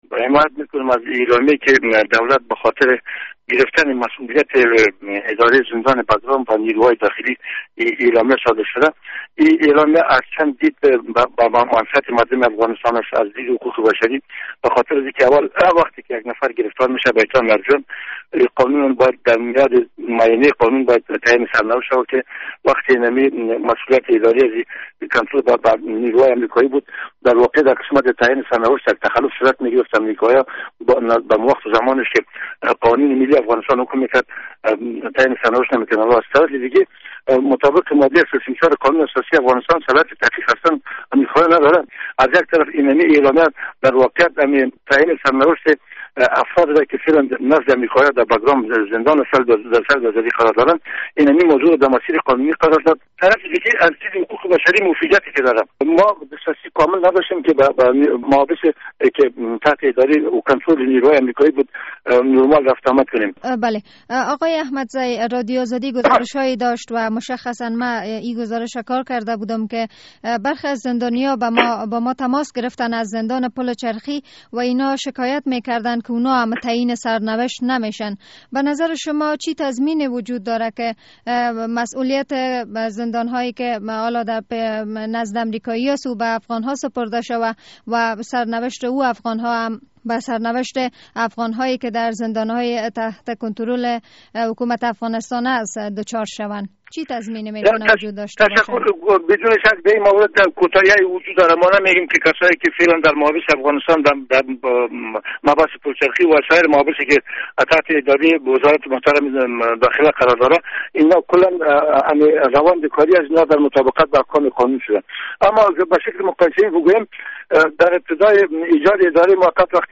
مصاحبه در مورد سپردن مسوولیت زندان بگرام به حکومت افغانستان